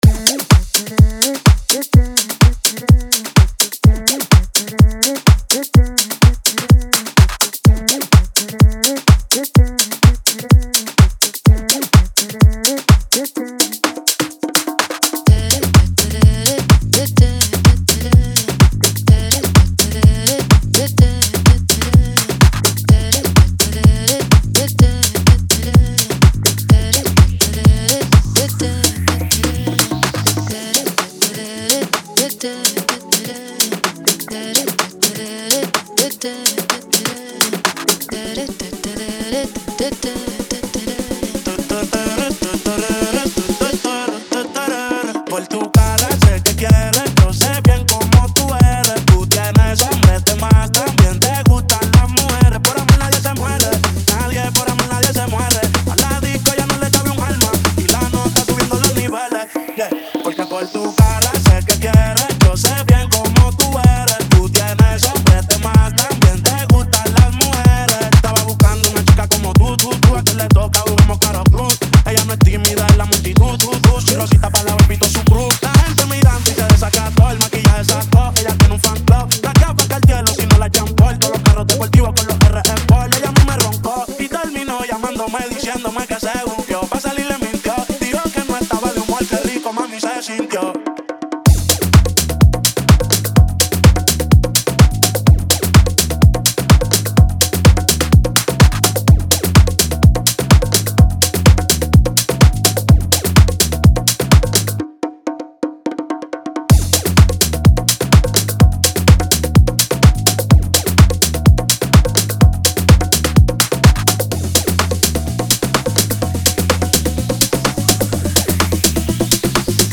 BPM: 126